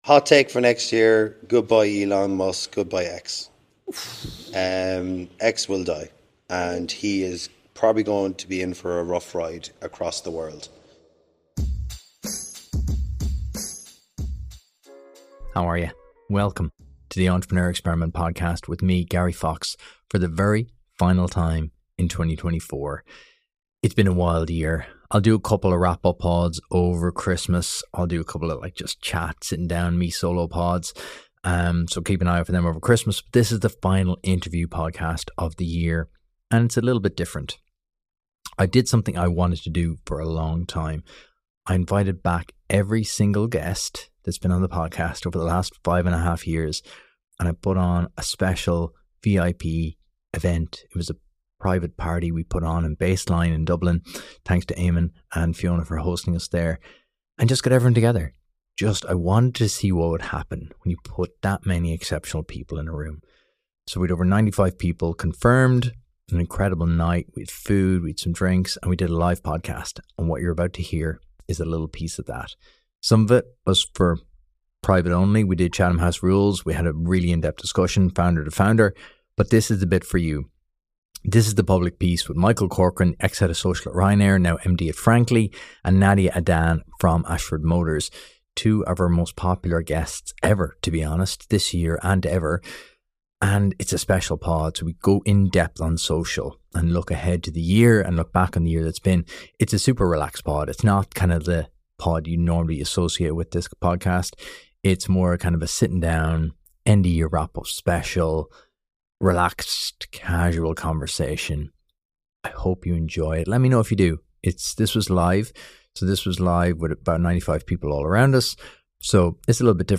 With over 70 founders in the audience, we also delved into the trends and predictions that will shape the next 12 months.
Shot live with a studio audience at Baseline in Dublin City.